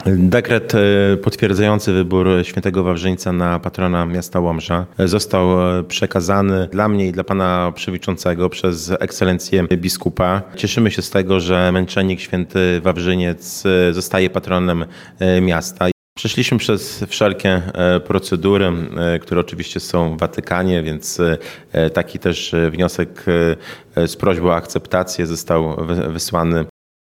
Prezydent Mariusz Chrzanowski nie ukrywa zadowolenia z faktu, że miasto będzie miało świętego patrona.